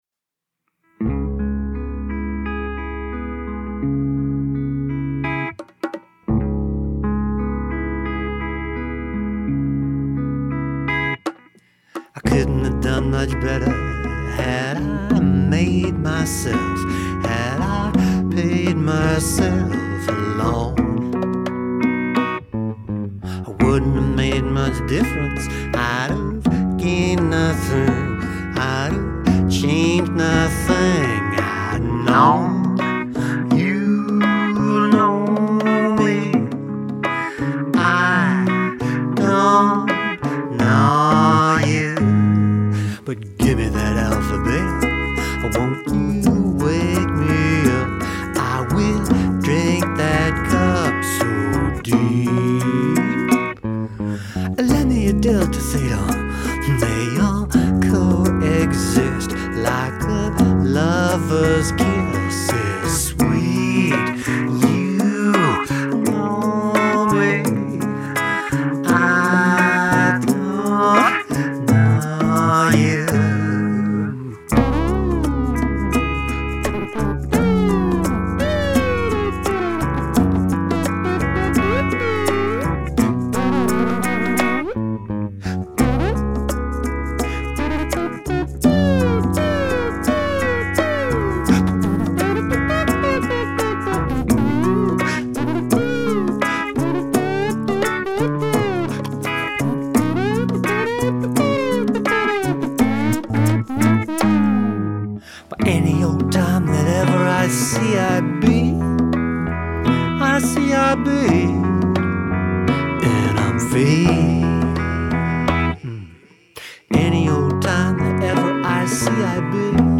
So here's the first full mix of this one.